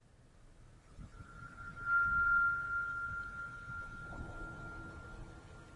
描述：轻轻的口哨声，回荡着的
标签： 港汇指数即 口哨 回声 门铃
声道立体声